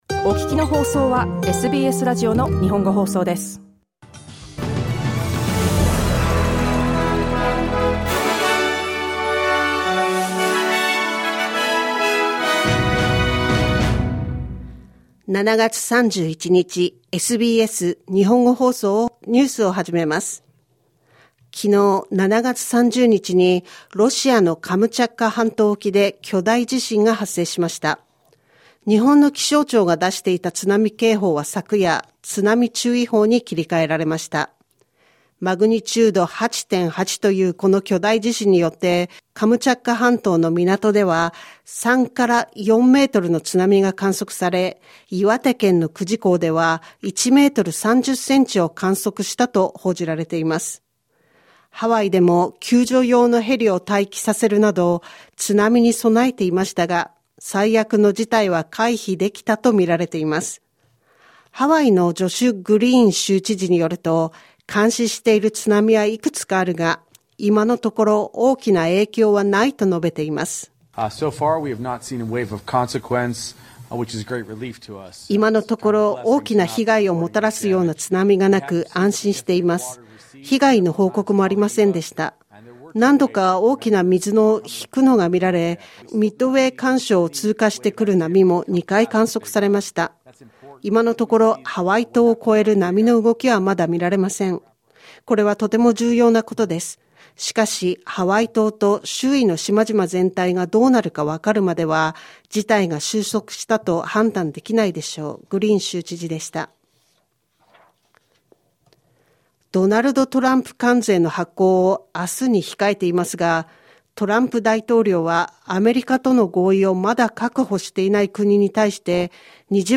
SBS日本語放送ニュース7月31日木曜日